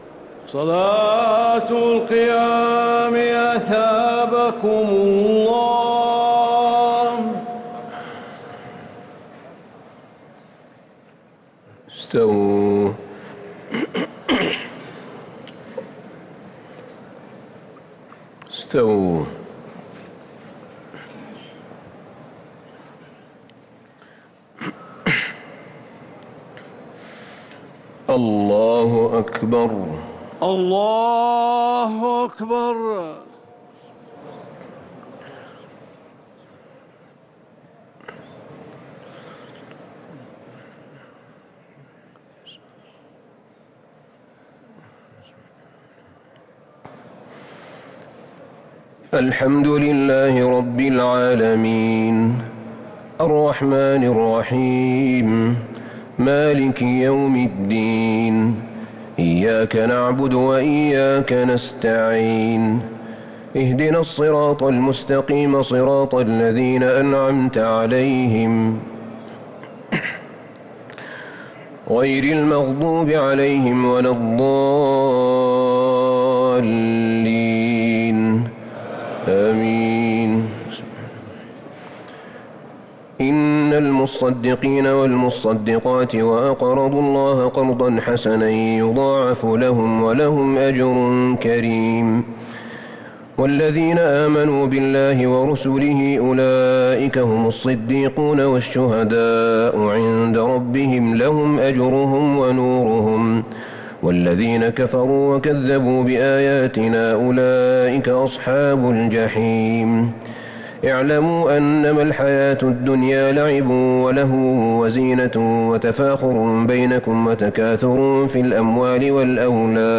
تراويح ليلة 28 رمضان 1443هـ من سورة الحديد 18- إلى سورة الجمعة | taraweeh 28 st niqht Ramadan 1443H from Surah Al-Hadid to Al-Jumu'a > تراويح الحرم النبوي عام 1443 🕌 > التراويح - تلاوات الحرمين